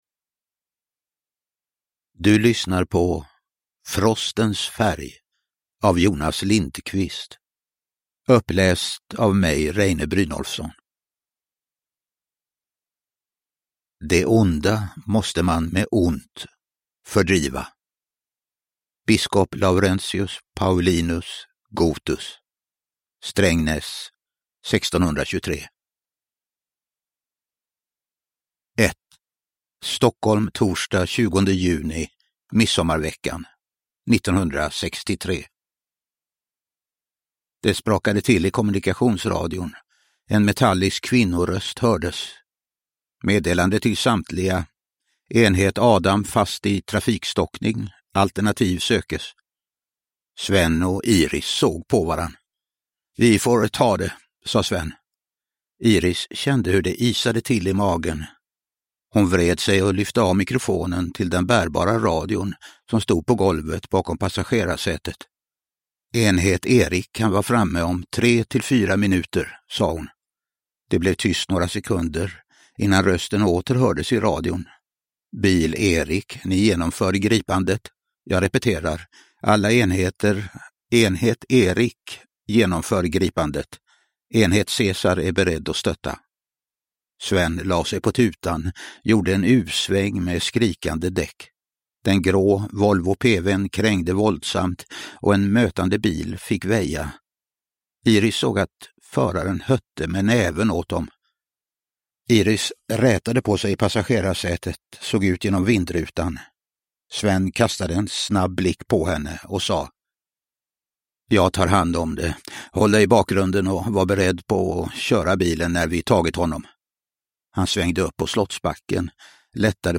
Uppläsare: Reine Brynolfsson
Ljudbok